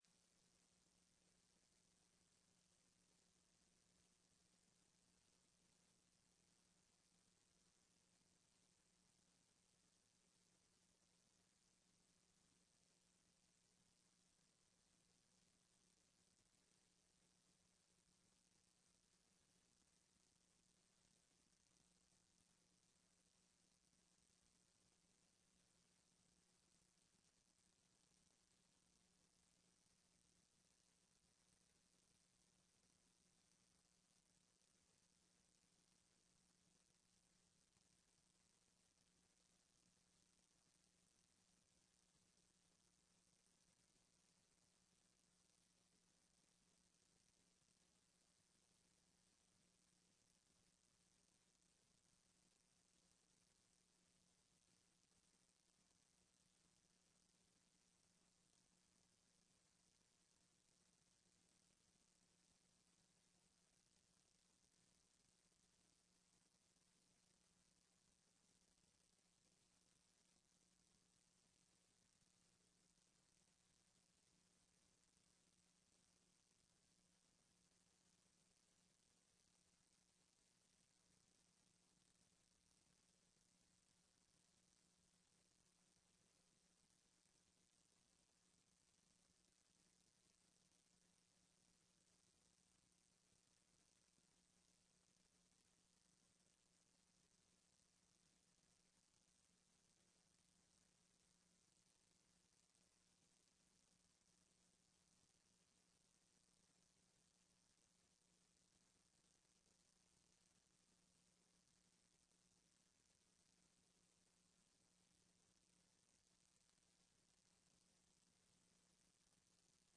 Sessão plenária do dia 25/11/15